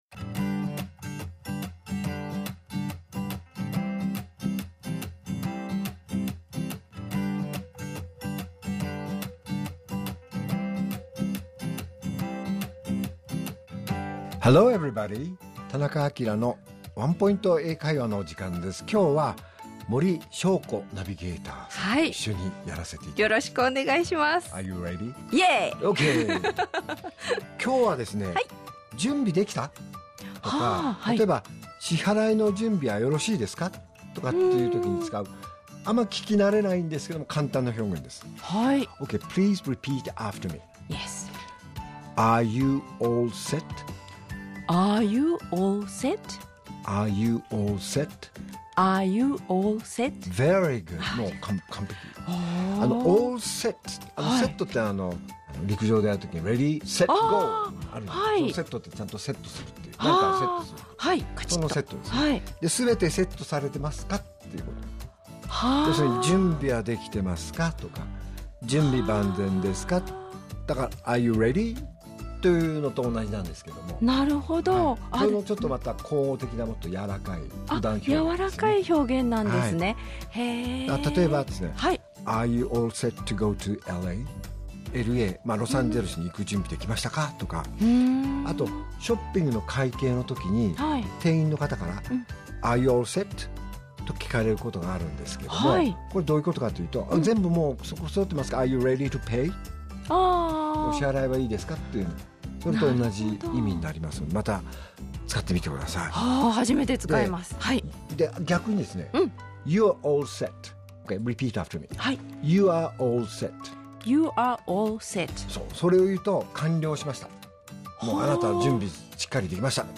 R7.1 AKILA市長のワンポイント英会話